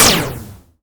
poly_shoot_spiky.wav